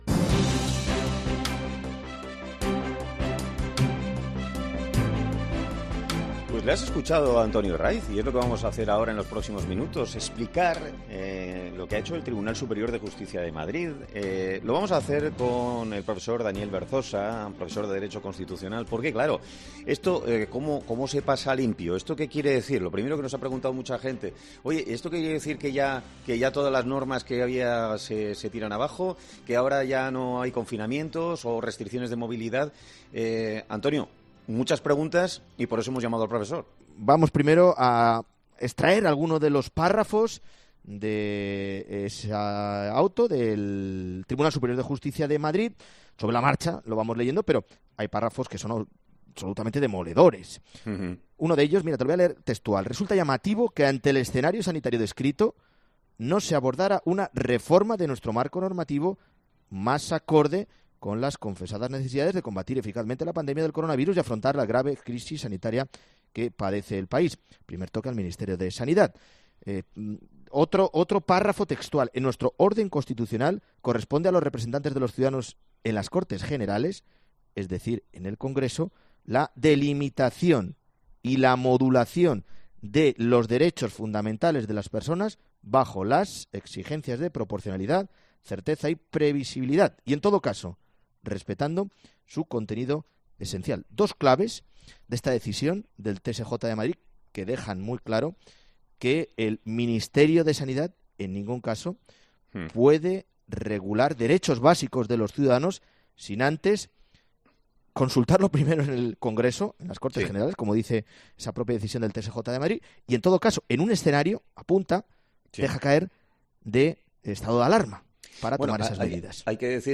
profesor de Derecho Constitucional